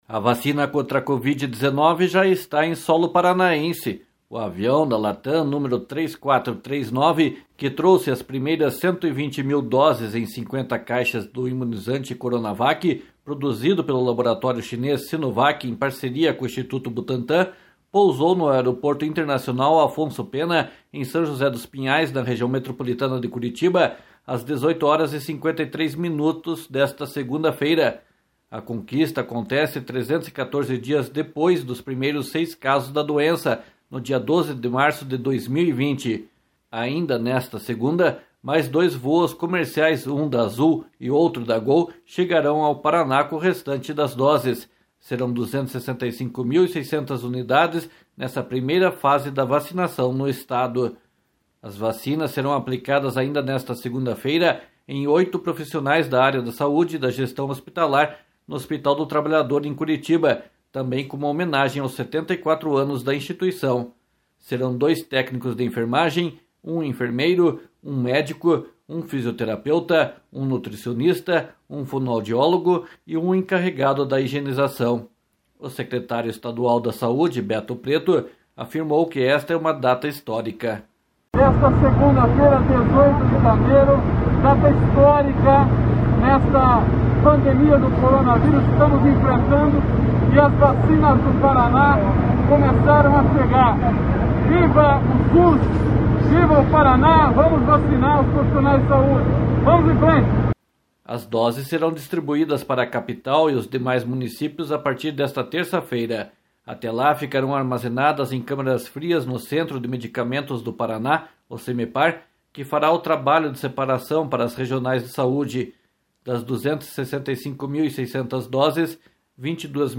O secretário estadual da saúde, Beto Preto, afirmou que esta é uma data histórica.